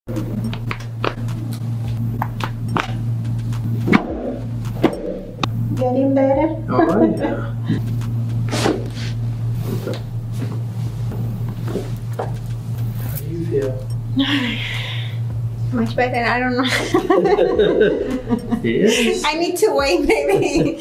ASMR Chiro CRACKS sound effects free download